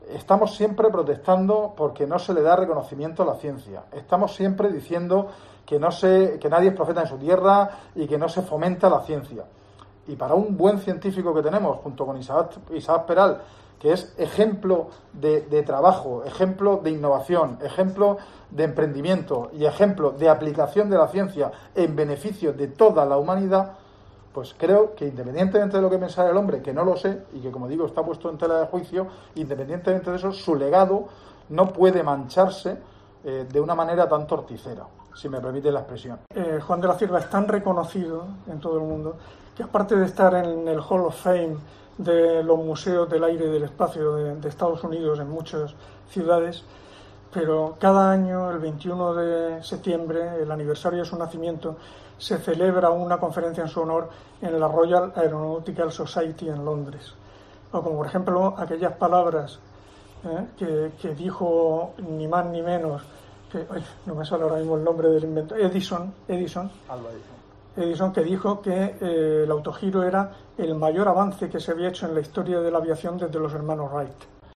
El consejero de Fomento, José Ramón Díez de Revenga ha defendido que, "independientemente de la ideología que pudiera tener o no" el inventor del autogiro, que "está puesta en discusión", su legado es "técnicamente indiscutible".